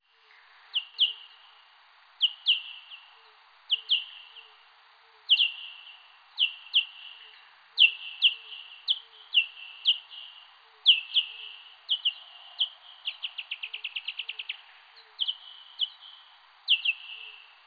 A denevérek hangja igen érdekes meglepetéseket tartogat magában.
-Denevér hangfájl-
Egyébként a mellékelt képen 24kHz körül látszik az az aprócska zaj, amit még nem sikerült kiszűrnöm.
40kHz_denever.flac